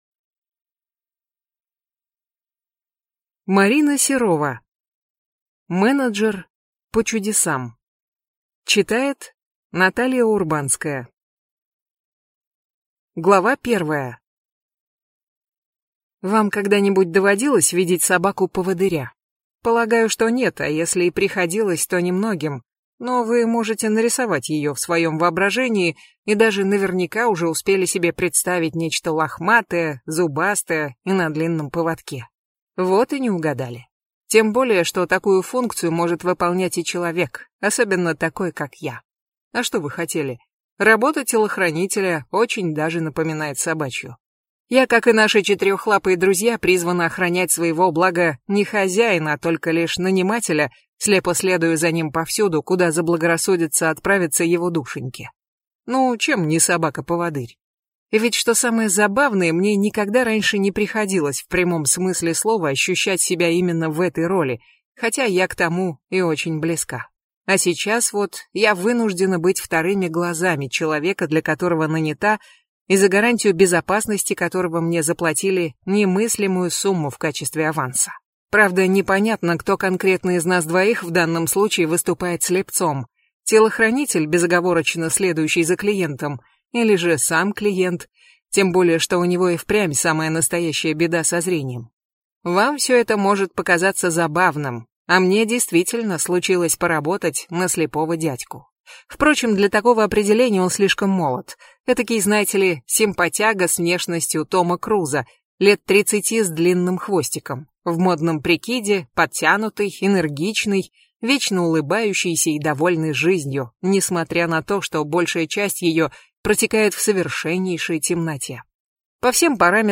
Аудиокнига Менеджер по чудесам | Библиотека аудиокниг